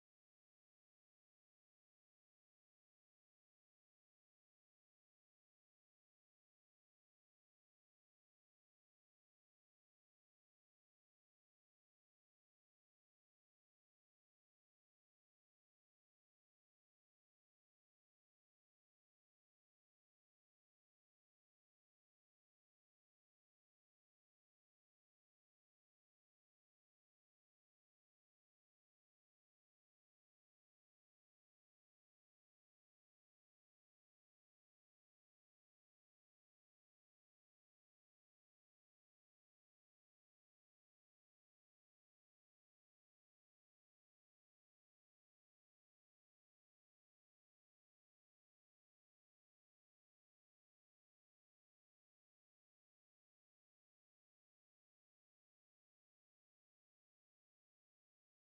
Capcut Electronic Sounds MTRCB Effects